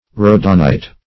rhodonite - definition of rhodonite - synonyms, pronunciation, spelling from Free Dictionary
Rhodonite \Rho"don*ite\, n. [Gr.